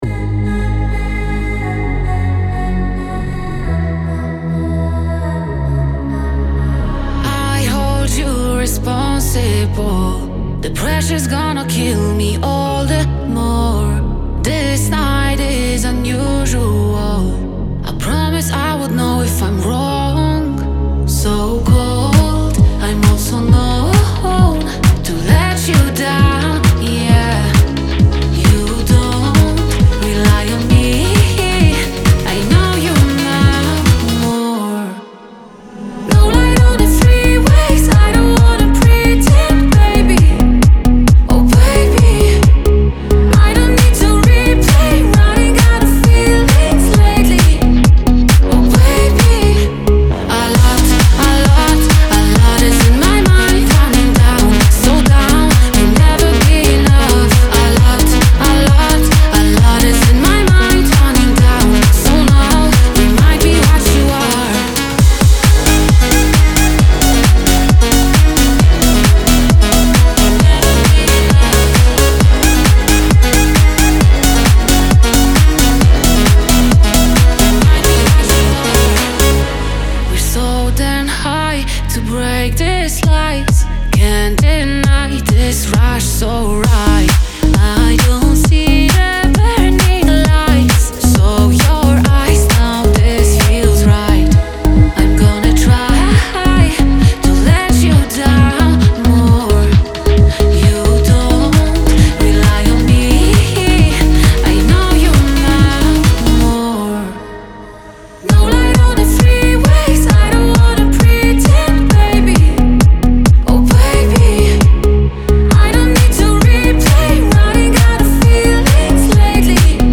klubowej, zachwycającej kolaboracji!